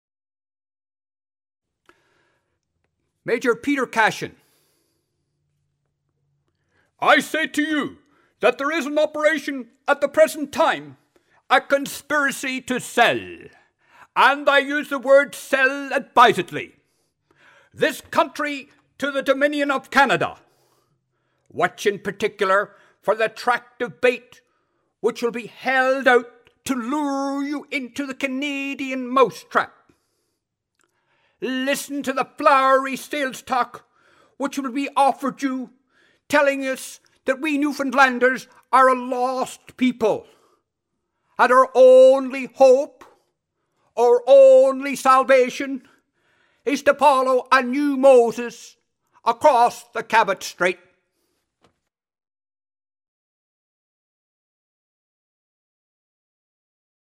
Speech #2: Anti-Confederate Major Peter Cashin